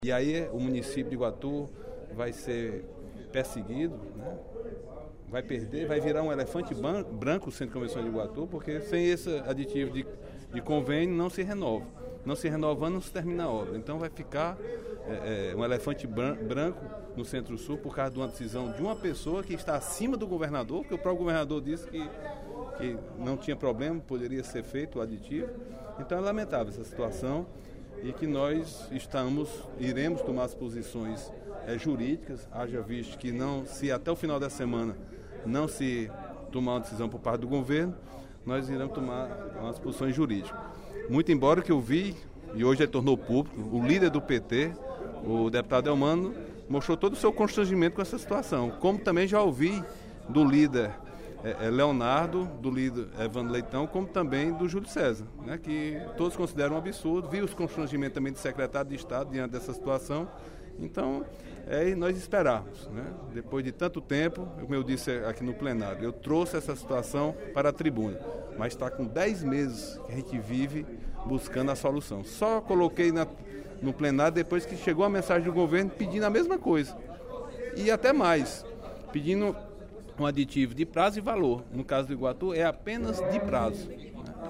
O deputado Agenor Neto (PMDB) fez pronunciamento nesta terça-feira (03/11), durante o primeiro expediente da sessão plenária, para cobrar do Governo do Estado a assinatura de aditivo do contrato para a construção do Centro de Convenções de Iguatu. Segundo ele, o aditivo não implicará aumento de despesas, apenas pede prorrogação do prazo de construção.